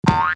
B_BOING.mp3